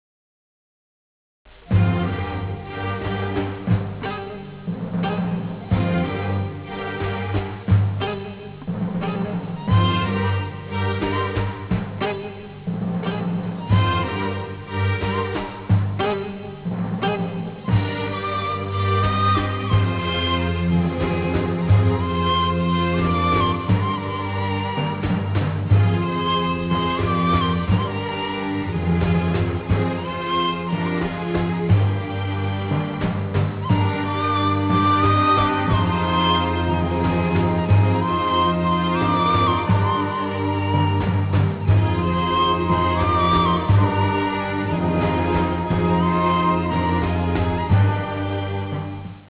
قطعه بی کلام نوحه